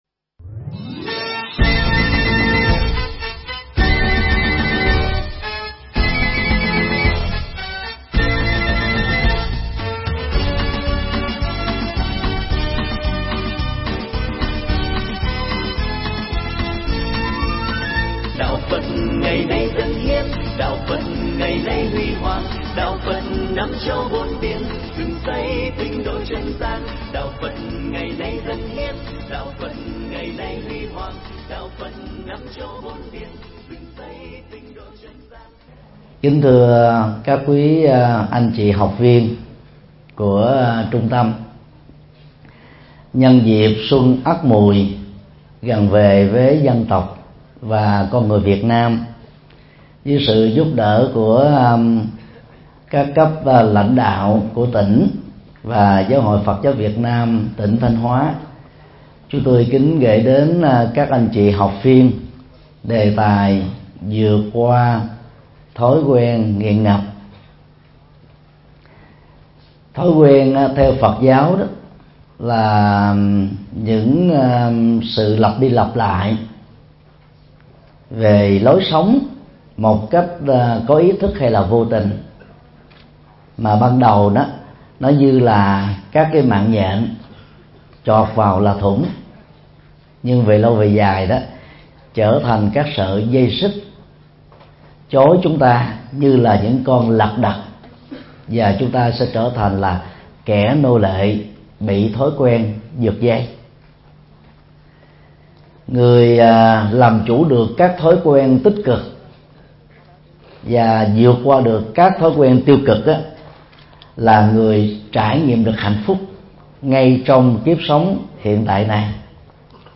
Mp3 Pháp Thoại Vượt Qua Thói Quen Nghiện Ngập - Thầy Thích Nhật Từ Giảng tại Trung tâm Giáo dục Lao động Xã hội Tỉnh Thanh Hóa,ngày 15 tháng 1 năm 2015